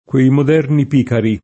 kUei mod$rni p&kari] (Boccaccio); antiq. piccaro [p&kkaro]: col lazzo del Piccaro Spagnuolo [kol l#zzo del p&kkaro Span’n’U0lo] (Lippi) — nel femm., sp. pícara [